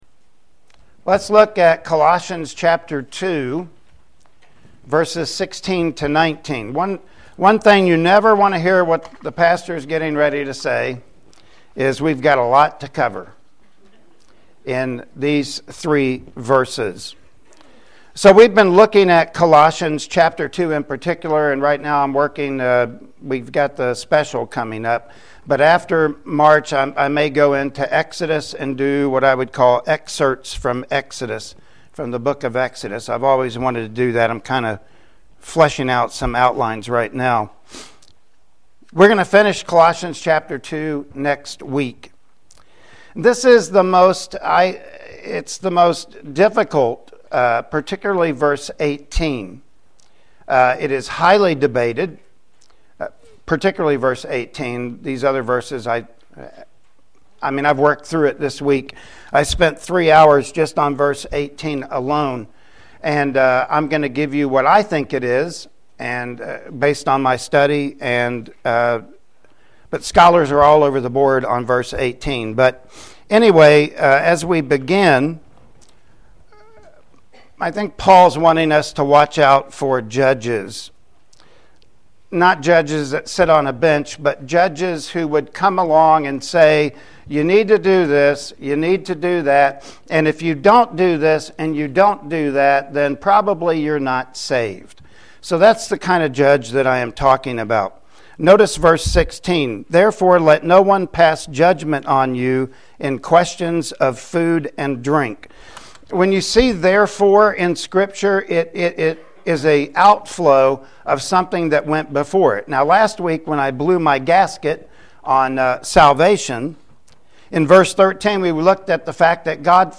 Colossians 2 Passage: Colossians 2:16-19 Service Type: Sunday Morning Worship Service Topics